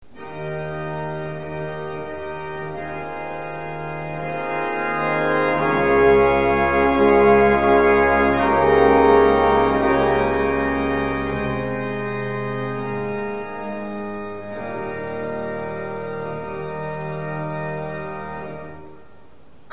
Swell to Pedal Just above the pedals is the Swell Pedal, which controls the volume of the pipes belonging to the Swell manual.
Swell Pedal Bourdon
sw_swell.mp3